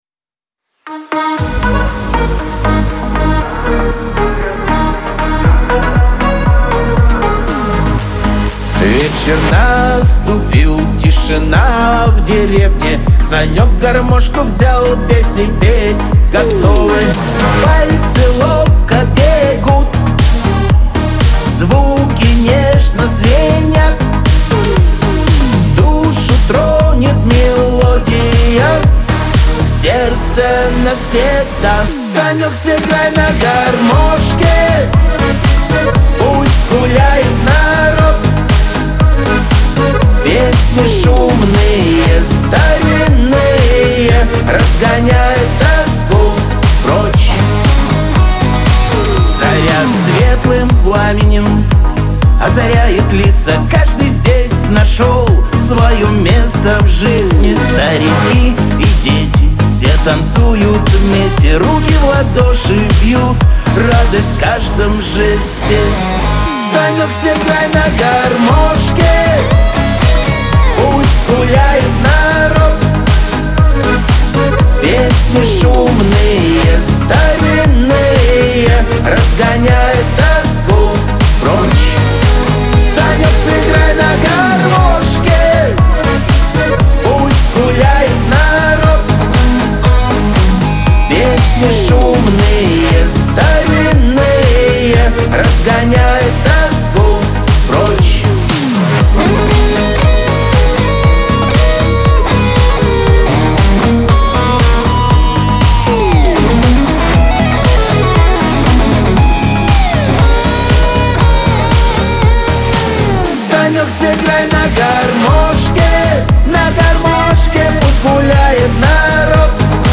диско
Веселая музыка , гармошка
Шансон